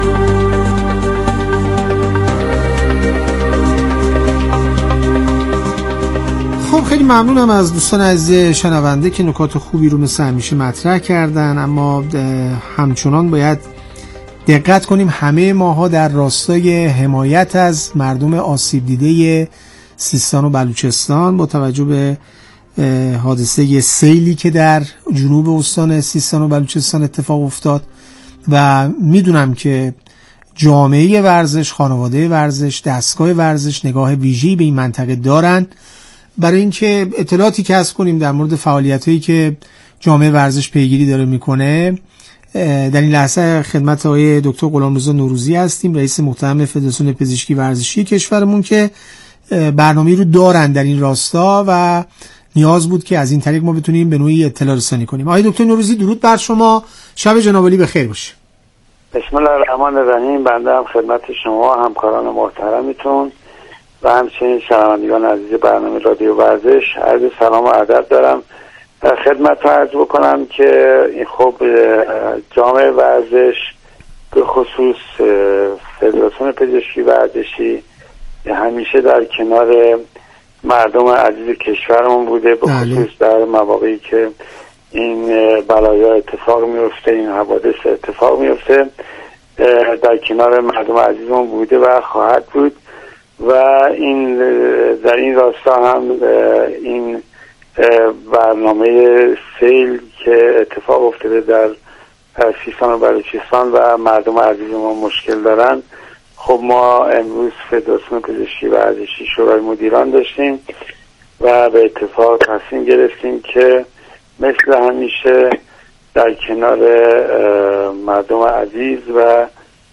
در گفت وگو با رادیو ورزش؛